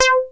RM12BASS C5.wav